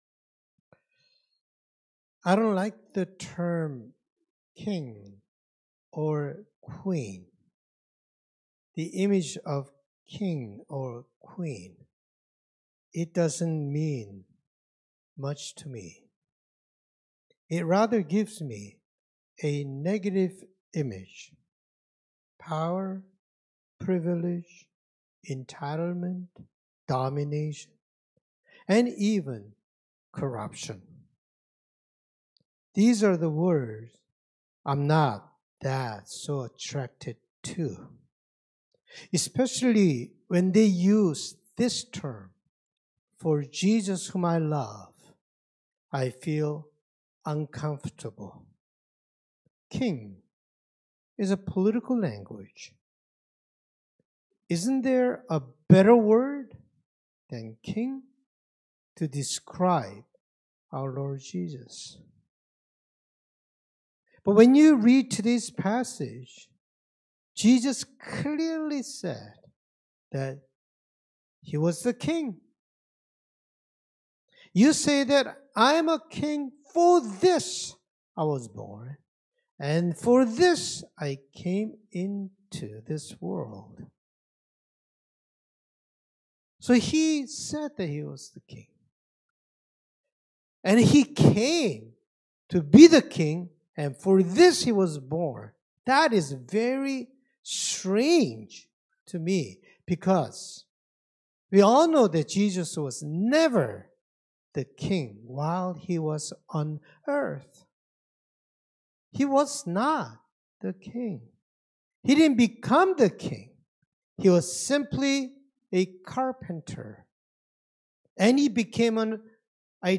Scripture Passage John 18:33-37 Worship Video Worship Audio Sermon Script I don’t like the term, king or queen.